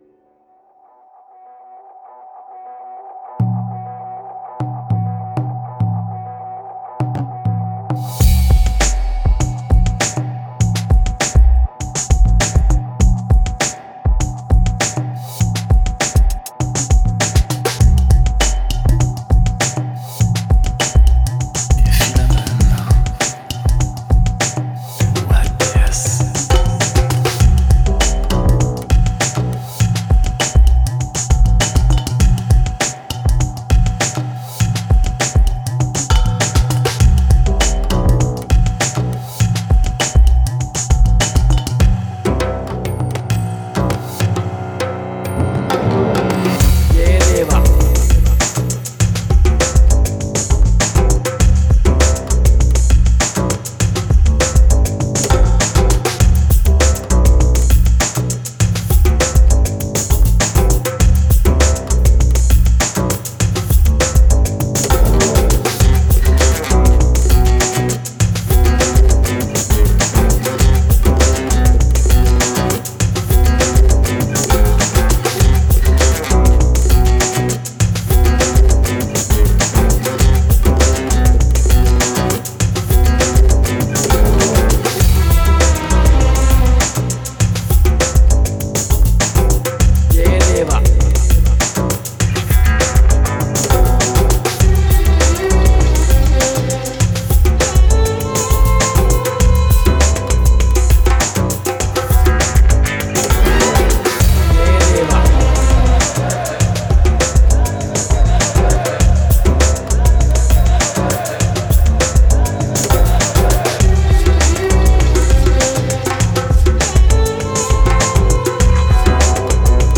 Genre: Psychill, Downtempo.